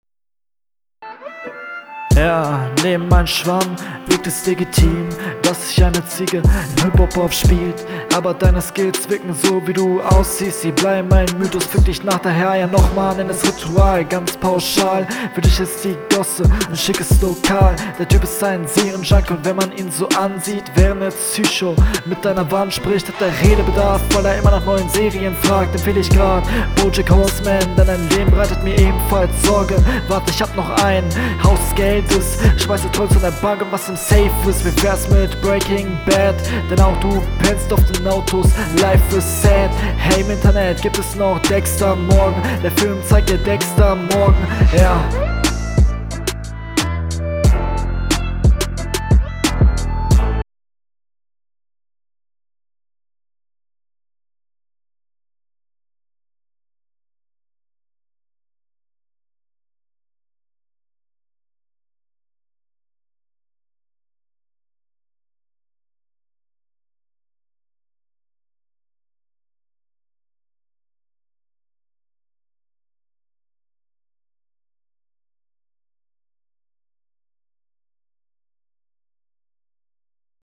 Der Stimmeinsatz ist kaum vorhanden, das ist fast geflüstert, die Delivery existiert nicht, das ist …
Flow: Du rappst noch merklich unroutiniert.
Flow: An einigen Stellen leider etwas offbeat.